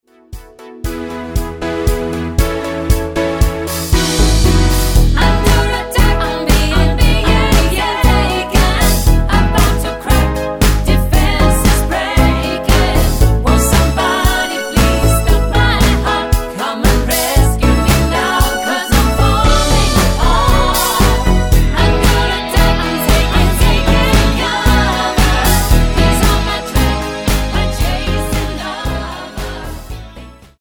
--> MP3 Demo abspielen...
Tonart:E mit Chor